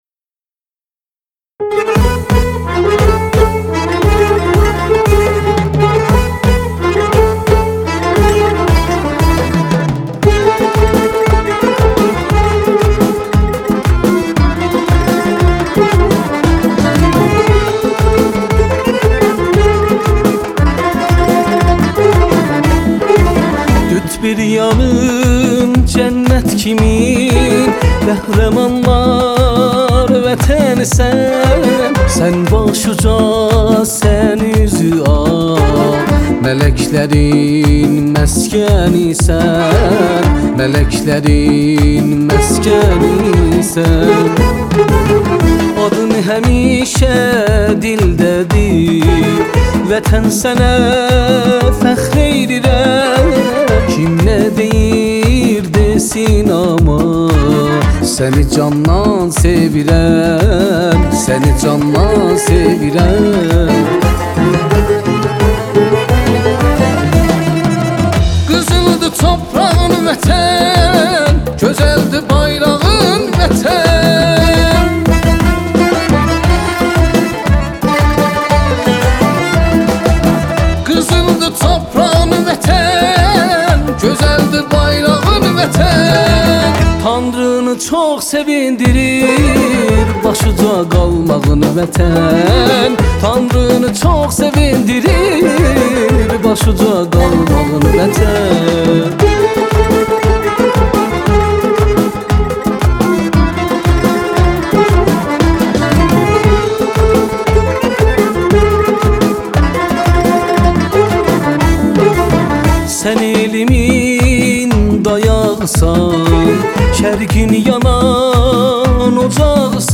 بخش دانلود آهنگ ترکی آرشیو